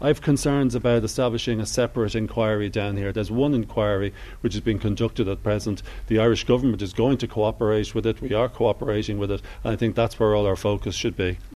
But Jim O’Callaghan says all the focus should be on the current one: